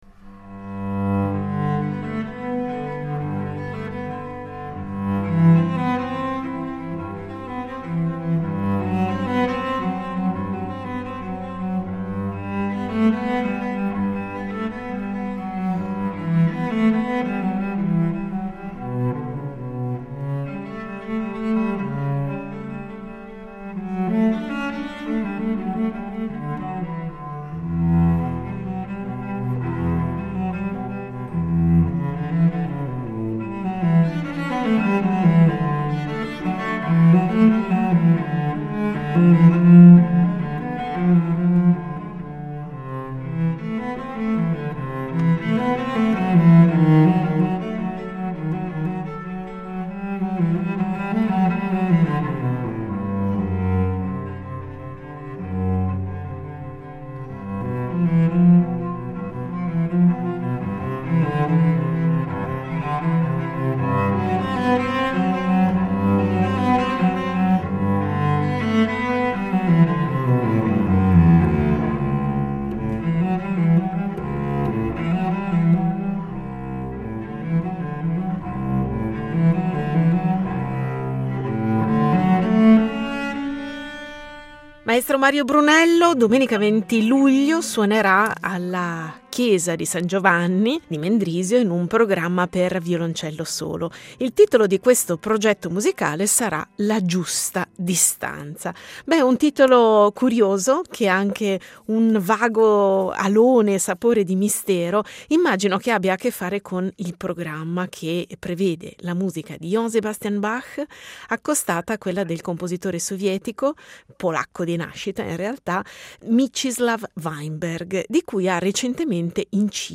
Il violoncellista italiano per i Concerti dei Serviti di Mendrisio.